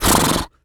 horse_breath_01.wav